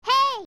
-A bell button, of course.
OOT_Navi_Hey.wav